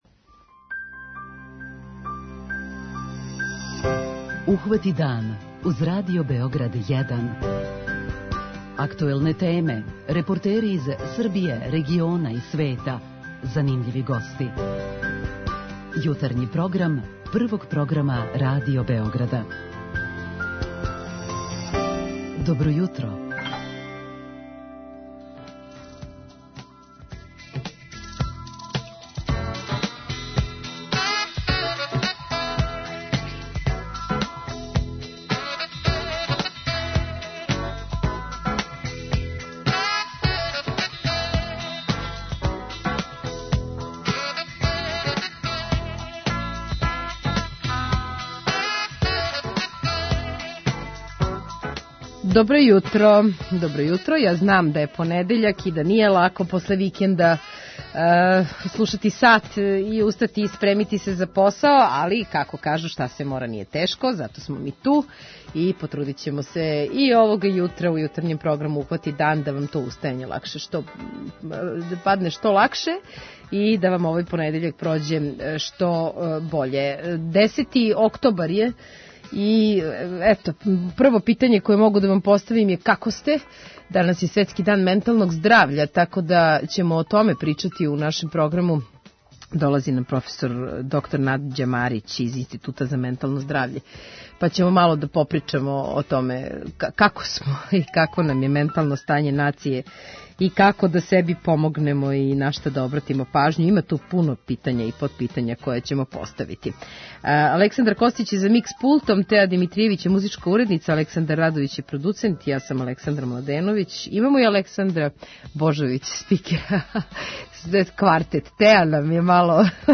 Посебан осврт имаћемо на шест деценија постојања Института за ментално здравље. преузми : 37.77 MB Ухвати дан Autor: Група аутора Јутарњи програм Радио Београда 1!